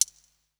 53 C HH 1 -L.wav